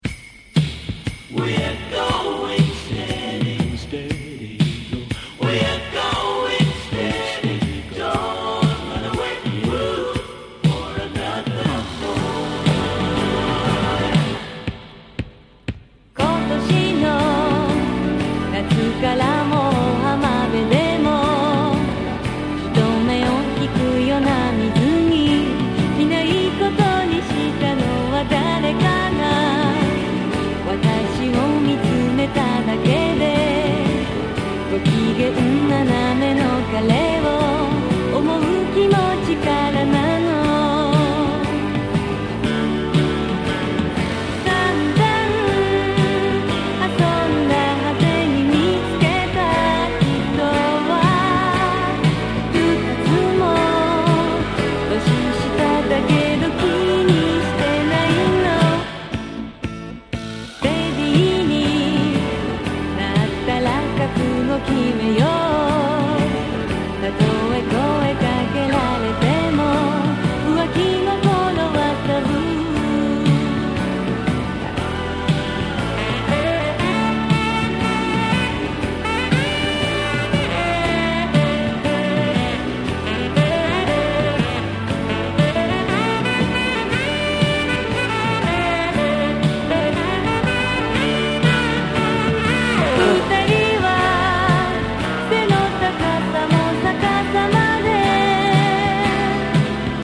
> 和モノ/JAPANESE GROOVE